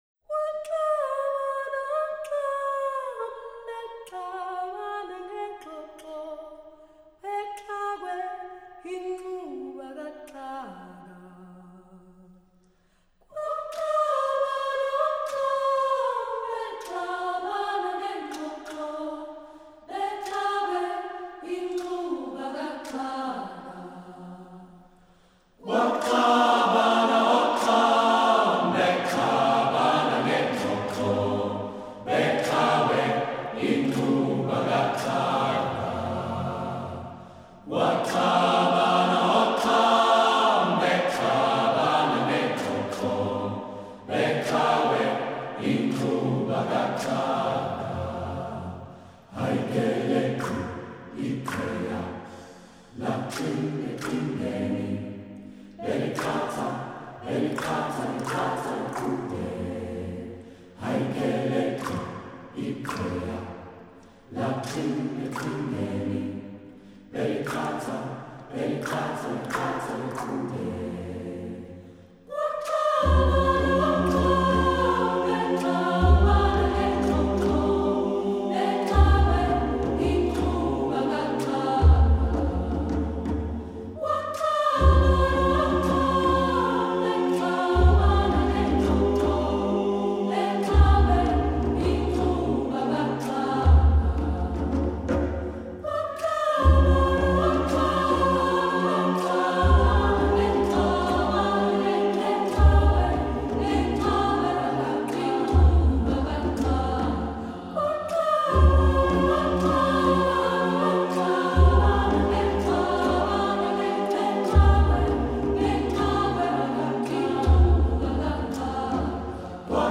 Composer: Traditional isiXhosa
Voicing: SATB and Percussion